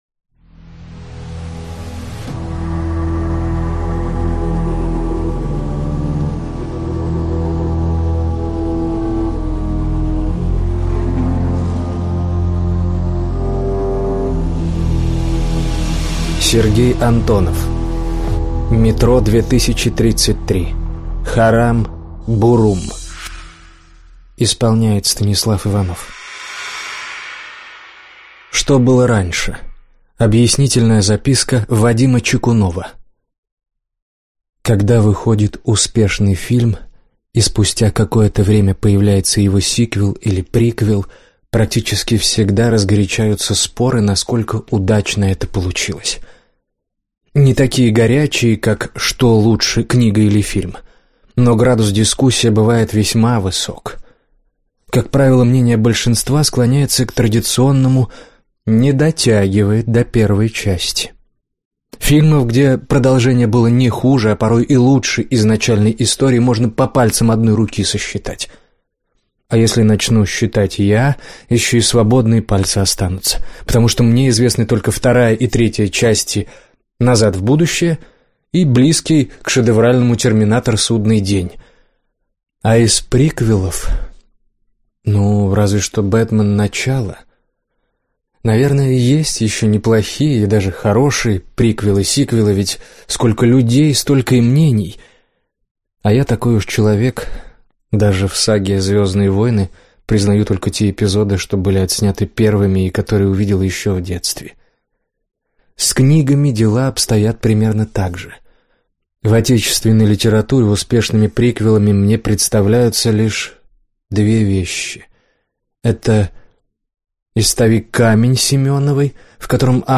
Аудиокнига Метро 2033: Харам Бурум | Библиотека аудиокниг